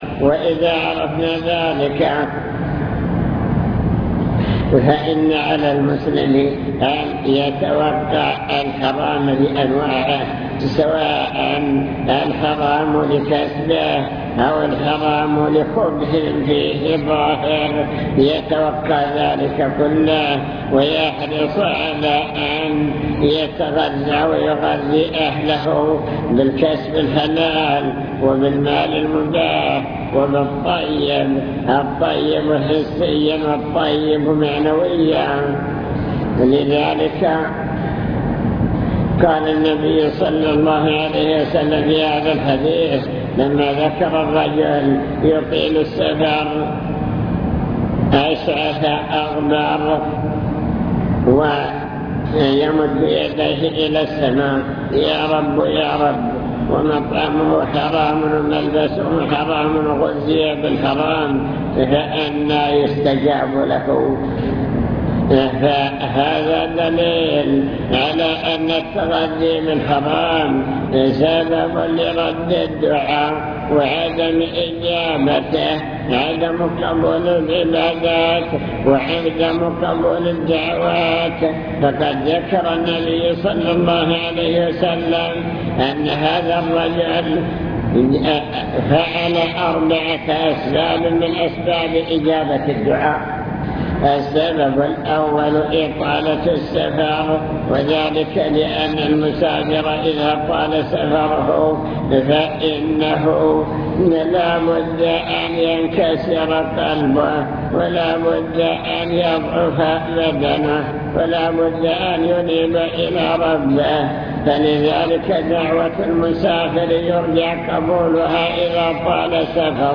المكتبة الصوتية  تسجيلات - محاضرات ودروس  محاضرة بعنوان المكسب الحلال والمكسب الحرام